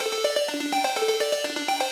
SaS_Arp04_125-E.wav